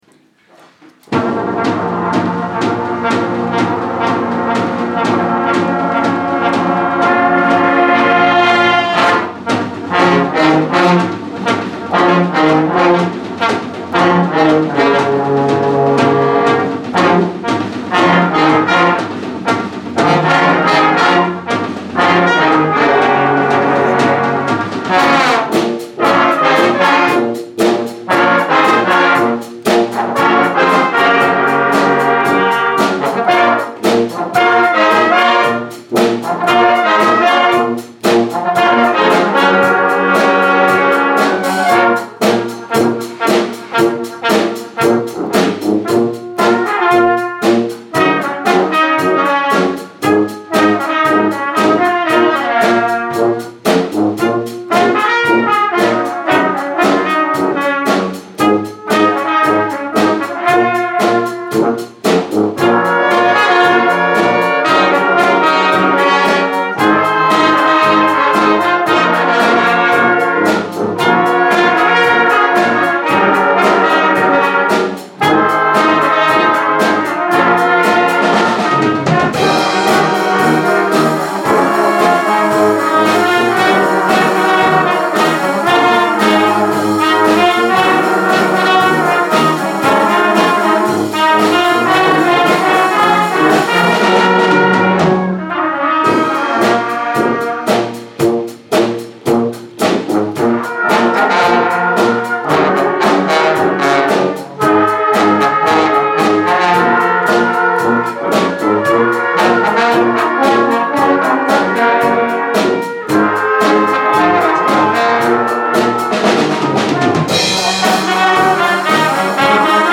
Senior brass March 2018